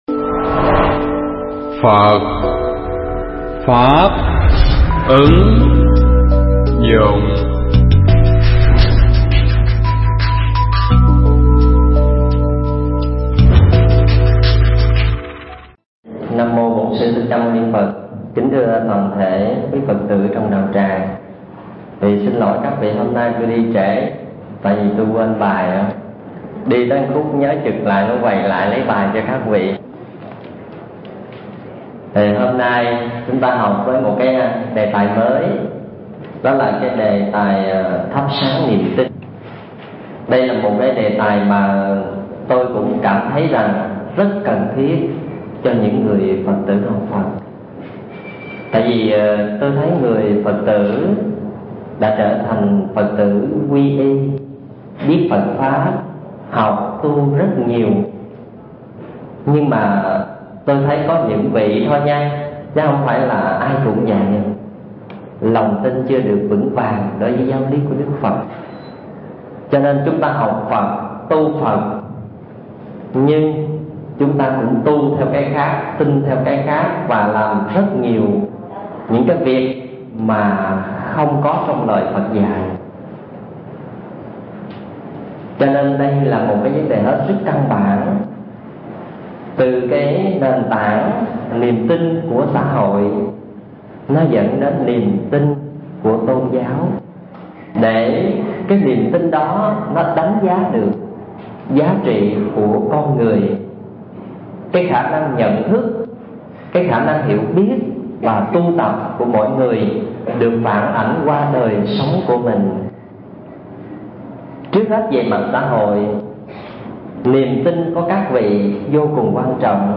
Pháp âm Thắp Sáng Niềm Tin